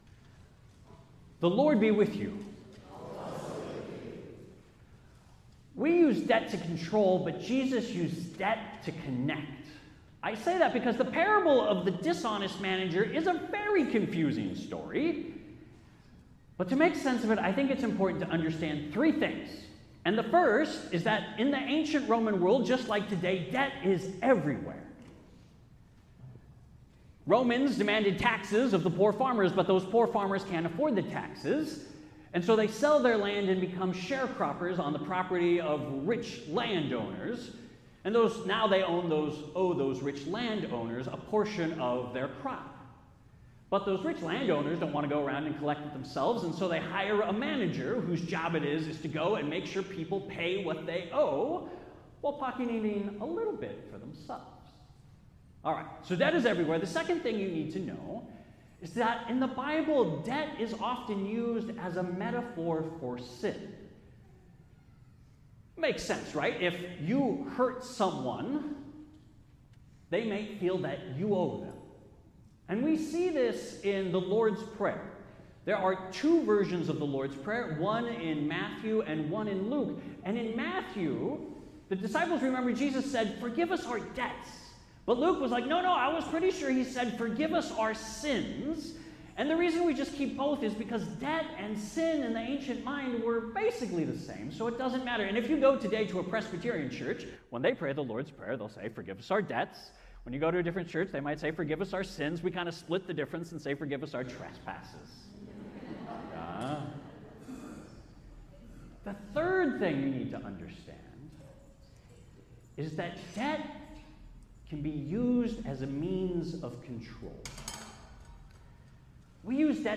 Sermons from Faith Lutheran Church | Faith Lutheran Church